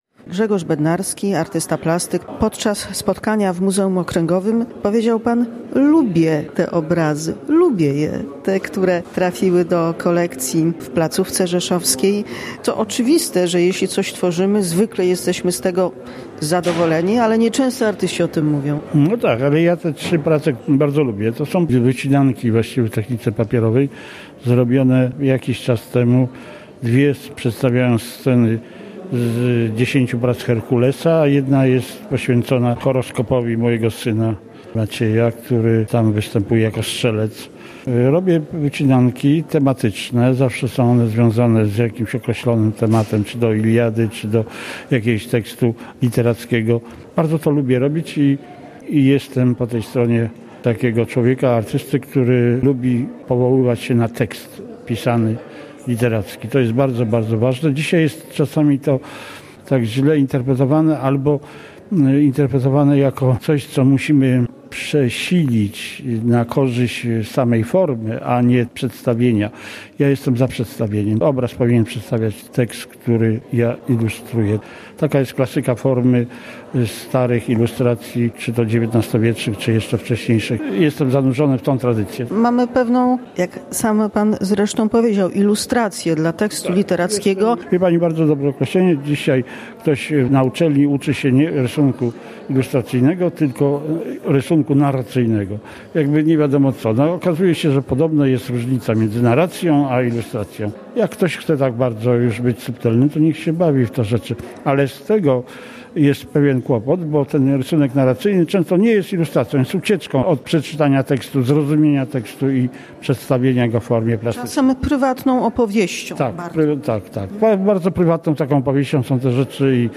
Artyści, obecni podczas prezentacji nabytków w siedzibie Muzeum Okręgowego (22.01), opowiadali o swoich inspiracjach i charakterystyce twórczości.